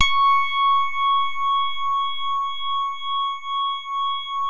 JAZZ SOFT#C5.wav